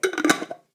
Cerrar un tarro de cristal
Cocina
Sonidos: Acciones humanas
Sonidos: Hogar